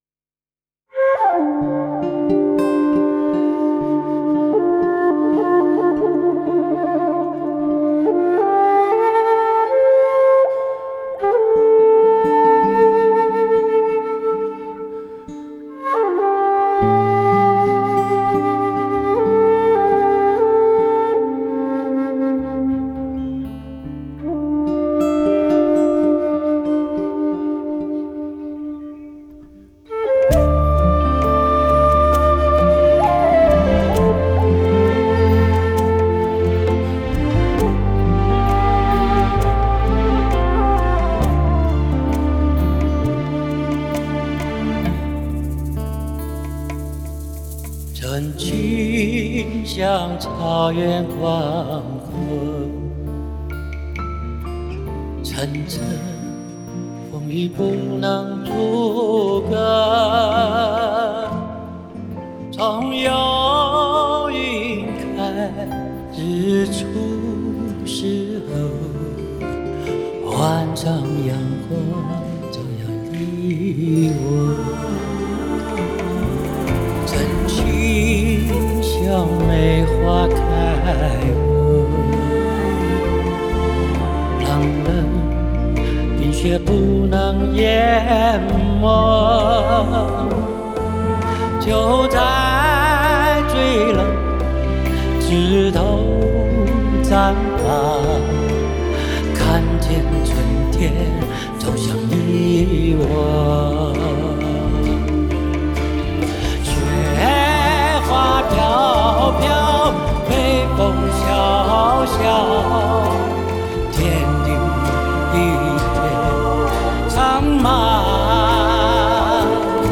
Ps：在线试听为压缩音质节选，体验无损音质请下载完整版
箫
吉他
键盘
贝斯
鼓手
弦乐